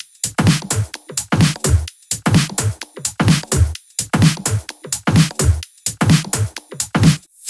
VFH3 128BPM Wobble House Kit
VFH3 128BPM Wobble House Kit 2.wav